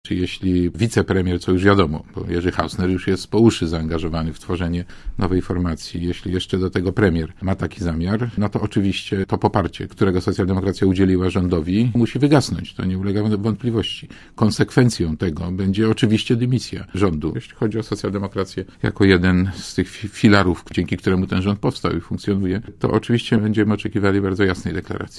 Premier powinien się podać do dymisji, jeśli przystąpi do nowej formacji - Partii Demokratycznej, tworzonej przez Władysława Frasyniuka i Jerzego Hausnera - mówi Marek Borowski, lider Socjaldemokracji Polskiej, poranny gość Radia ZET.
Mówi Marek Borowski